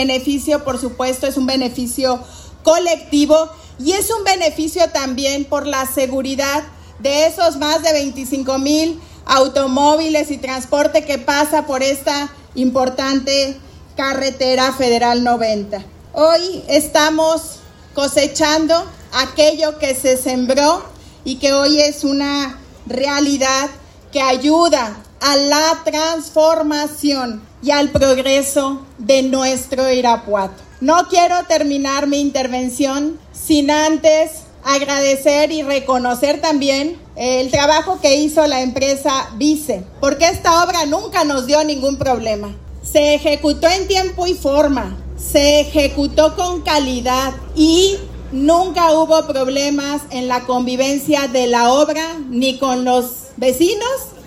Diego Sinhue Rodríguez Vallejo, gobernador